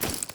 pistol_holster.ogg